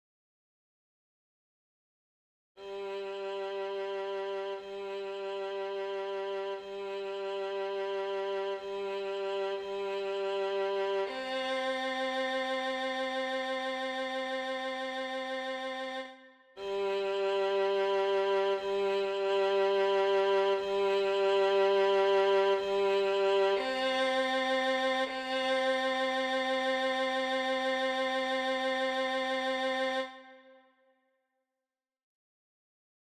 Key written in: C Major
How many parts: 4
Type: SATB